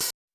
hihat01.wav